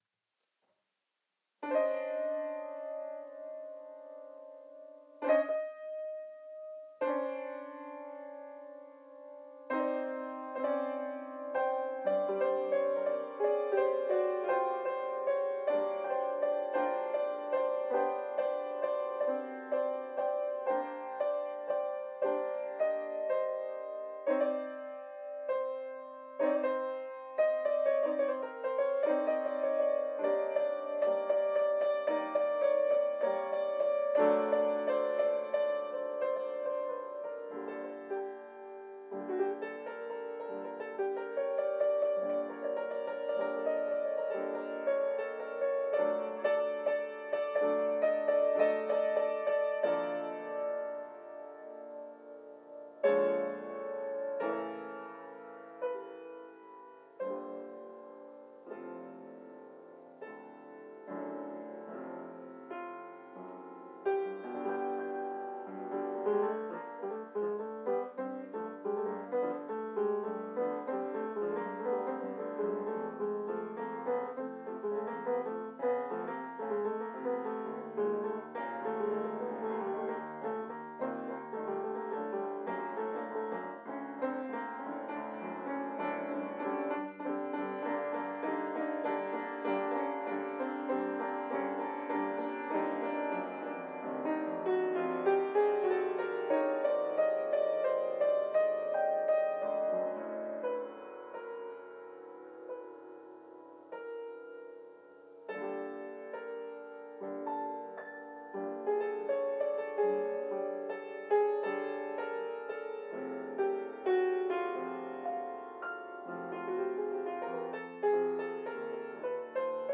J’ai des dizaines d’enregistrements de mes improvisations, qui durent de 1 à 3 minutes en général, et le choix déposé ici est très problématique, car j’ai la conscience aiguë de ne pas avoir les moyens subjectifs d’opérer une « bonne » sélection.
Je pratique le ‘pianon’ plus que le piano.
…En ignorant, sans partitions, improvisant sur un éventail plus que limité d’accords, de rythmes et de tonalités.